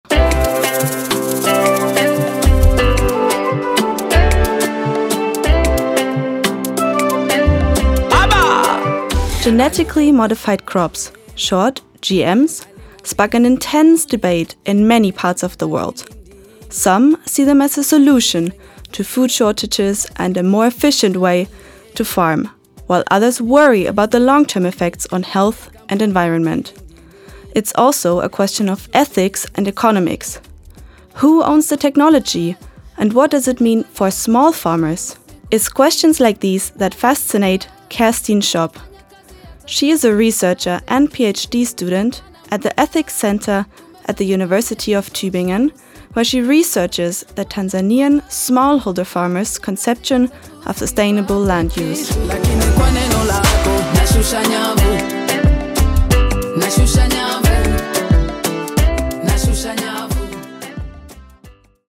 We have an interview for you in the form of a podcast about sustainable agriculture in Tanzania. It's all about genetically modified crops and their potential in the Global South, as well as in the Global North.